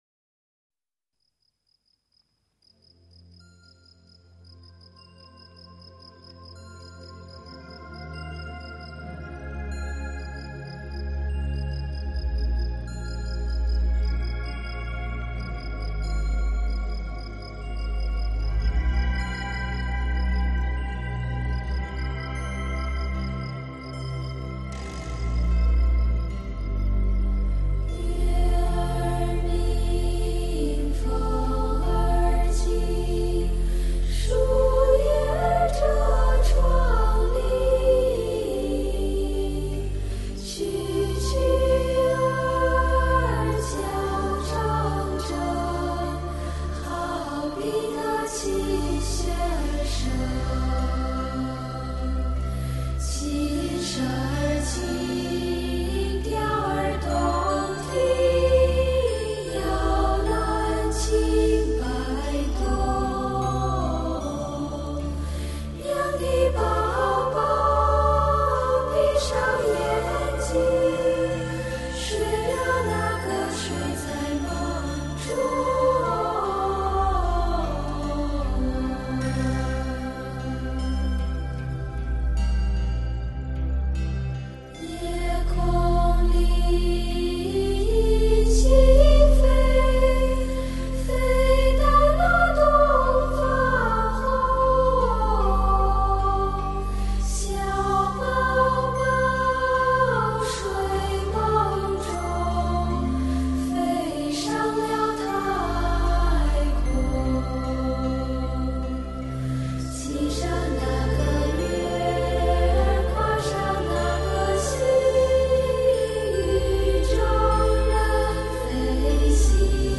以清澈优美的和声，征服国内外乐迷，
本辑收录了各地少数民族的摇篮曲，
唱出摇篮曲特有的温柔情怀，
格清新，宛如天籁，
柔美的和声，清晰的录音，